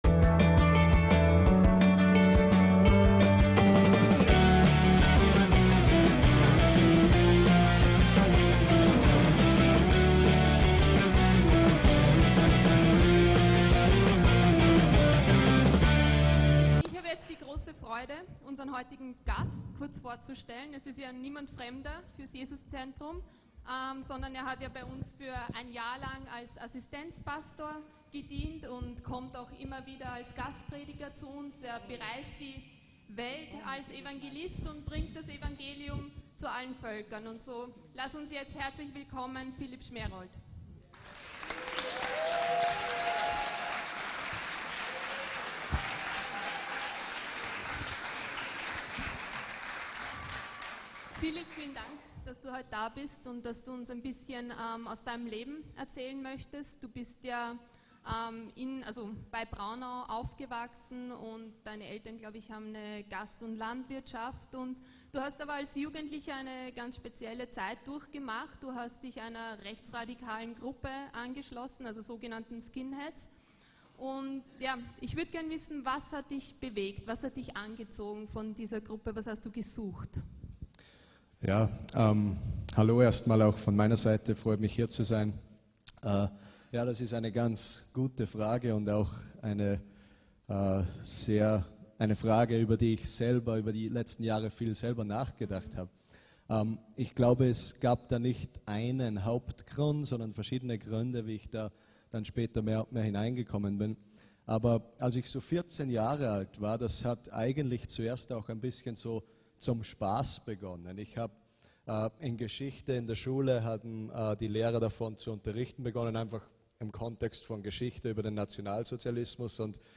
VON HASS ZU LIEBE + INTERVIEW